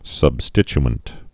(səb-stĭch-ənt)